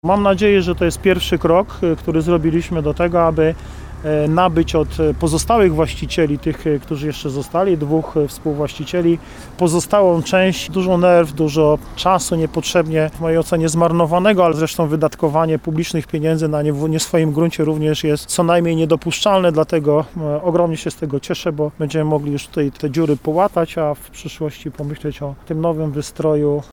mówi Paweł Osiewała, prezydent Sieradza.